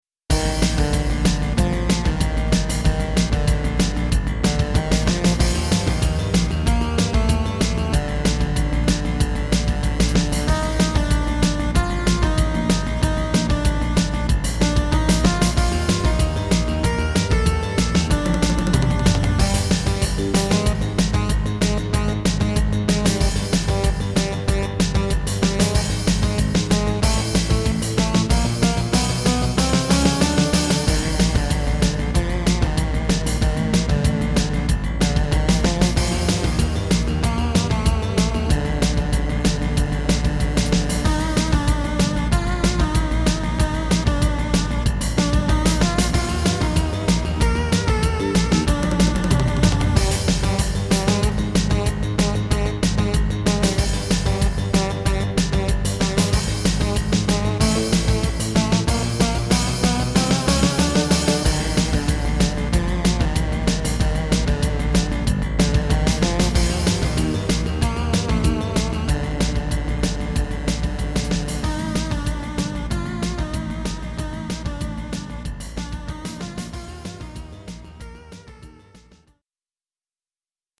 MT-32 MIDI conversion
As recorded from the original Roland MT-32 score